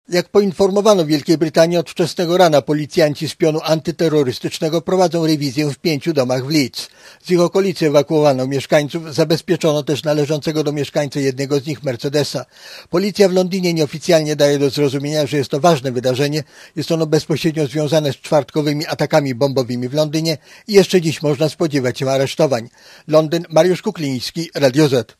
londyn_-_leeds_-_rewizje.mp3